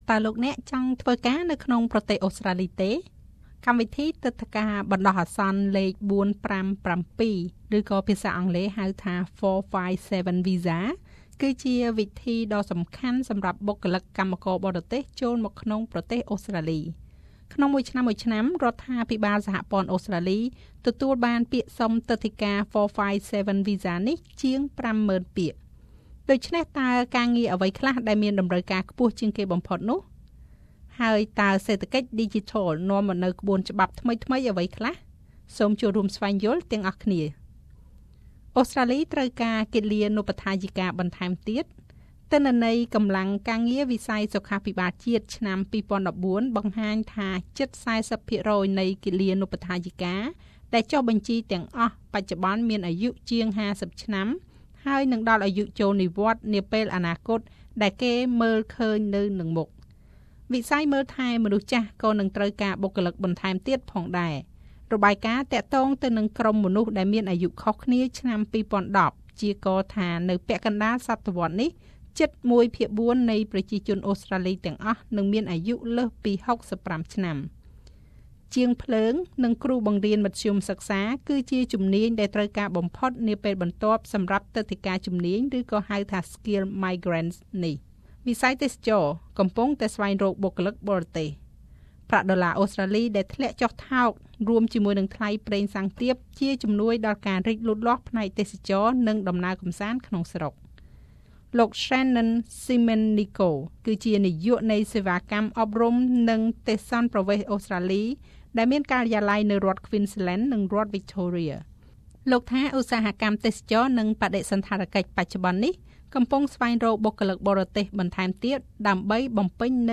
សម្រាប់លោកអ្នកដែលមានបំណងរស់នៅក្នុងប្រទេសអូស្រ្តាលីតាមរយៈទិដ្ឋាការជំនាញ សូមស្តាប់នូវបទសម្ភាសន៍ខាងក្រោម៖